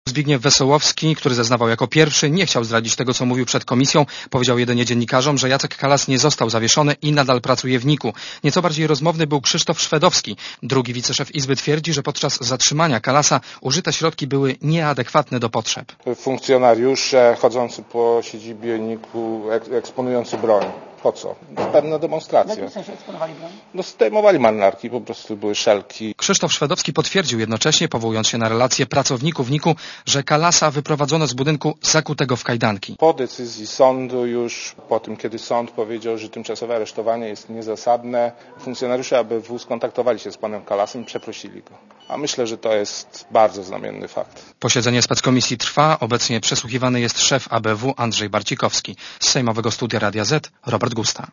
Posłuchaj relacji reportera Radia Zet (200Kb)